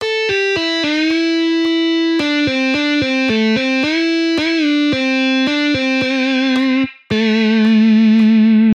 The next lick I’m using has only one rest: an eighth rest before the final note.
A lick with only one rest
The last note, an A, is the root note of the minor pentatonic scale I’m using. The note before the last creates suspense, accentuated more by the heavy use of vibrato, and wants to resolve to the last note.